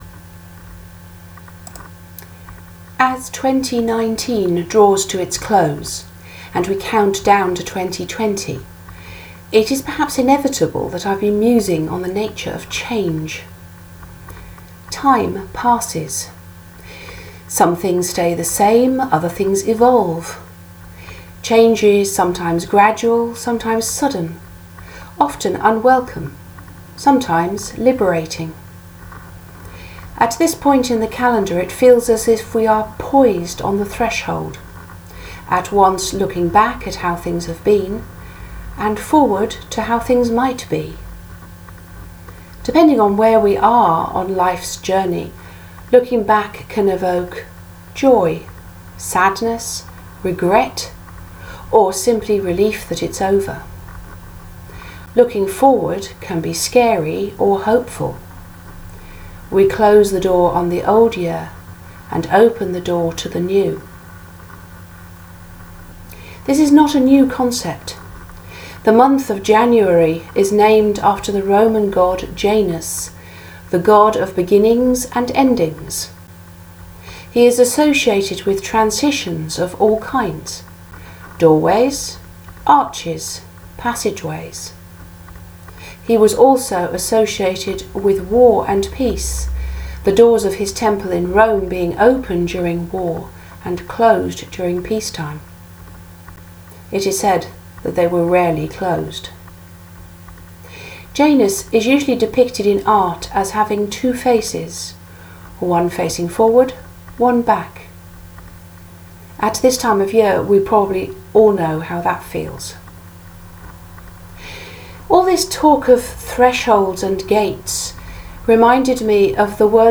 I’ve not used the technology before, and I’m just using the Voice Recording function on my laptop, not a proper mic, so the quality is a bit scratchy.